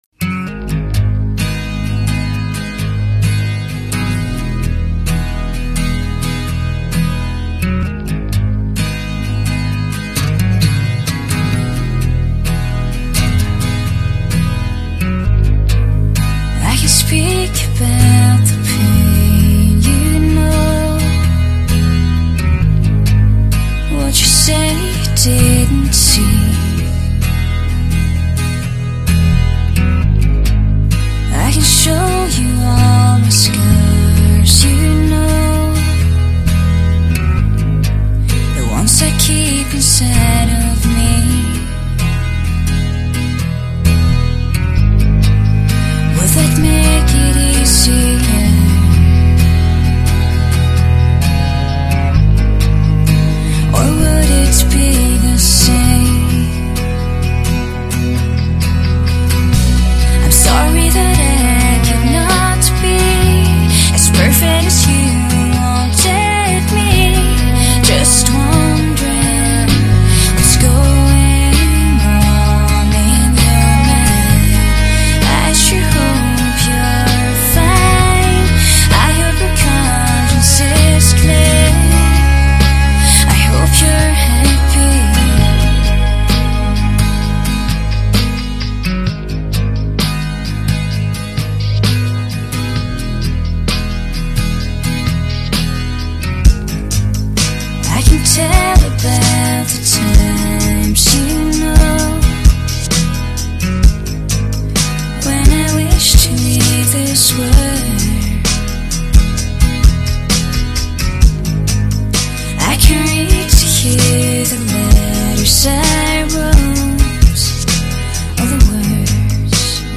类型：欧美流行乐